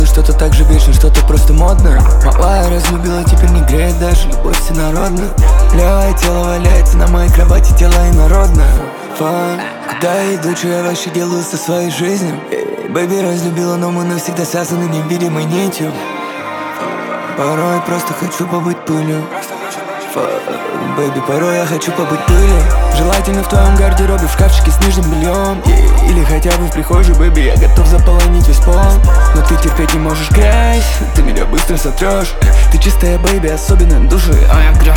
Жанр: Хип-Хоп / Рэп / Русский рэп / Русские